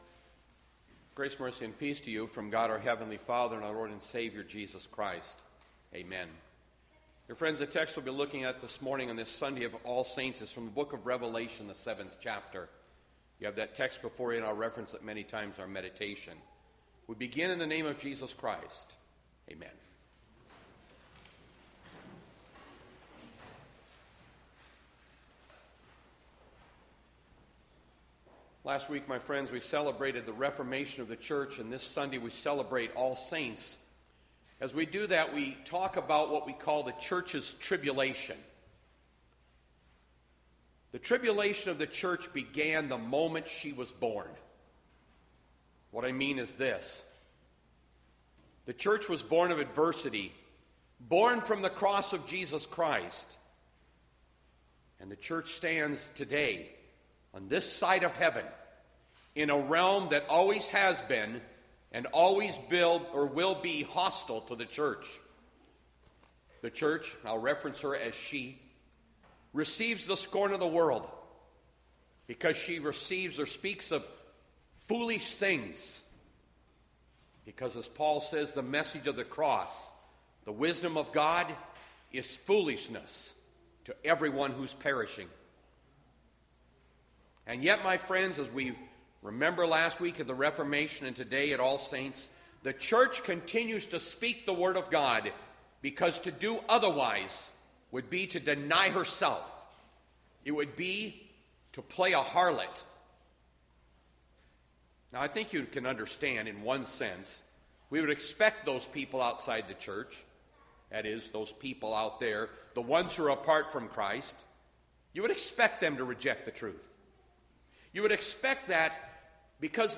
Bethlehem Lutheran Church, Mason City, Iowa - Sermon Archive Nov 1, 2020